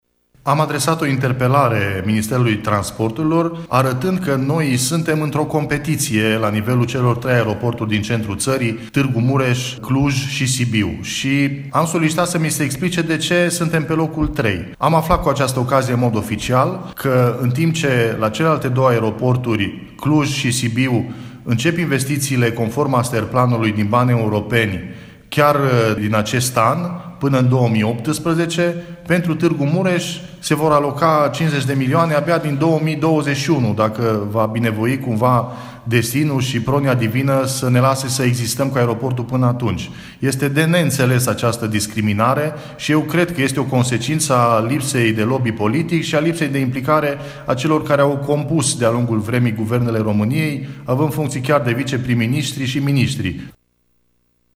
Afirmația îi aparține deputatului PMP de Mureș, Marius Pașcan, care, într-o conferință de presă desfășurată astăzi, a arătat faptul că, în urma unei interpelări adresate Ministrului Transporturilor, Răzvan Cuc, a aflat că Aeroportul Transilvania Tîrgu Mureș va primi finanțare doar din anul 2021, pe când aeroporturile Cluj Napoca și Sibiu vor primi fonduri pentru dezvoltare încă din acest an.